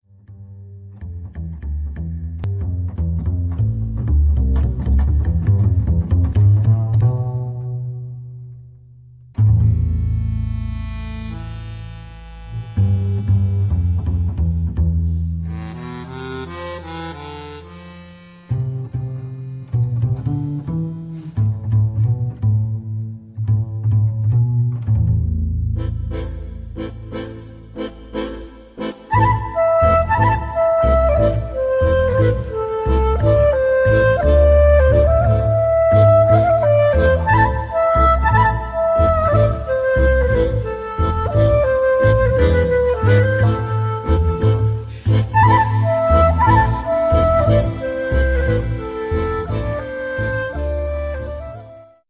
początek utworu to tradycyjna żydowska hora
klarnet clarinet
akordeon accordion
kontrabas double-bass